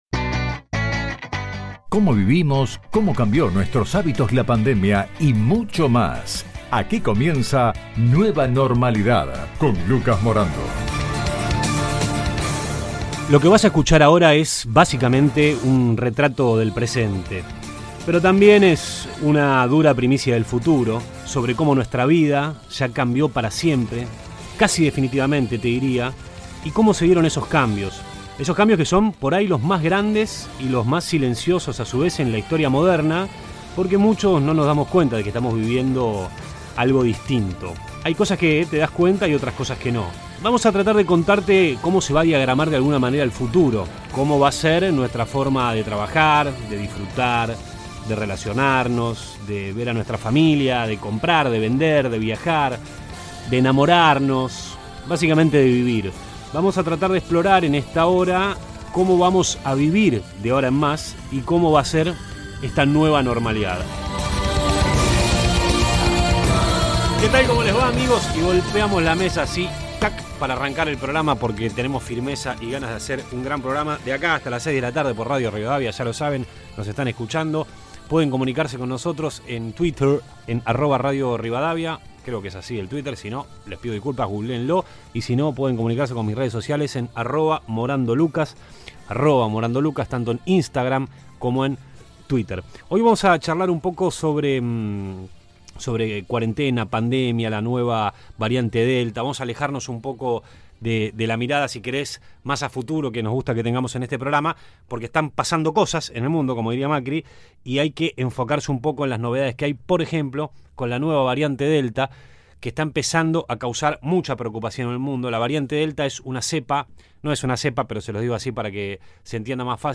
La variante más peligrosa del Covid va cambiar la forma de la pandemia en la Argentina. Acá, la opinión de una viróloga argentina que investiga en el Reino Unido.